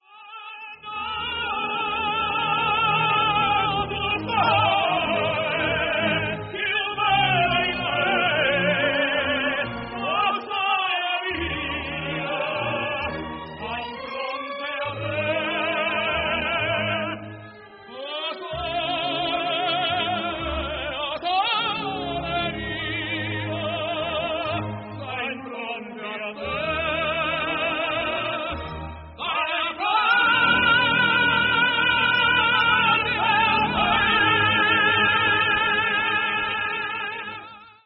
Trio Masculino Fragmento (audio/mpeg)
Un trío de tenores